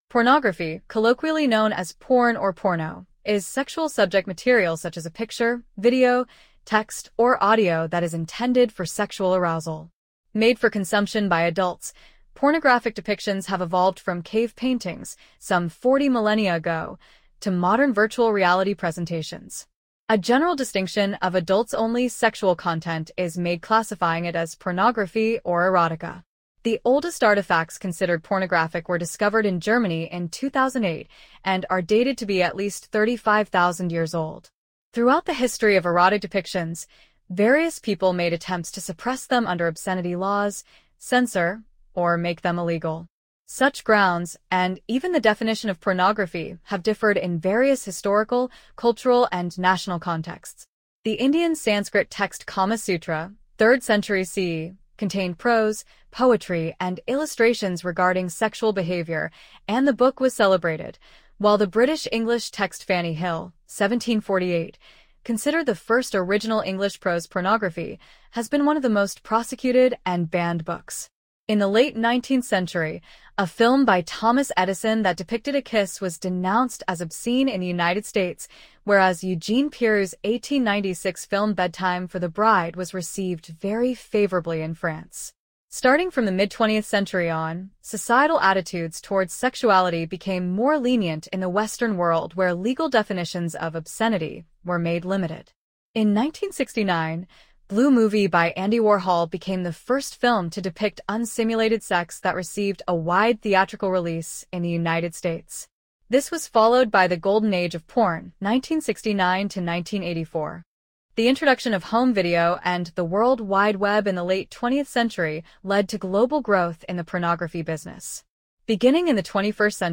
Pornography narrated by AI-generated voice using SoniTranslate as described here
voice en-US-AvaMultilingualNeural-Female